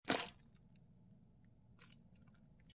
Mud Plops And Movement, Wet And Thin, X4